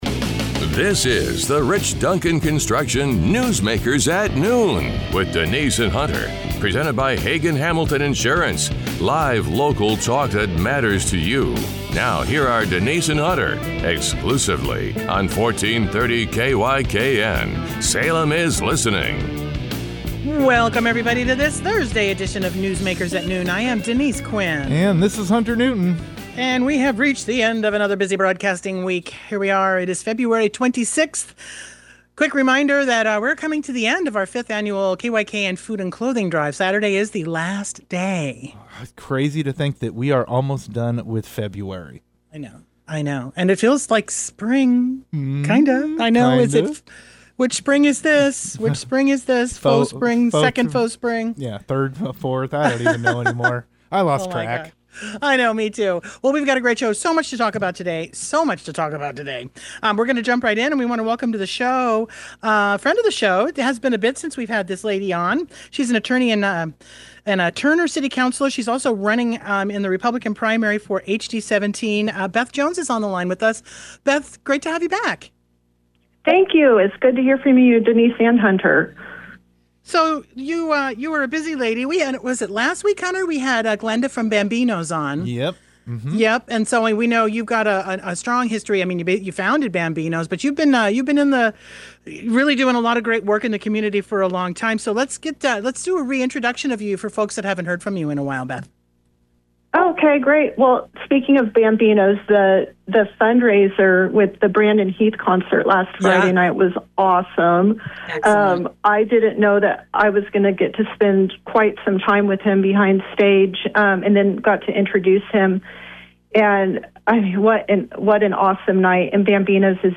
The hosts also review how districts typically handle liability and supervision in these situations.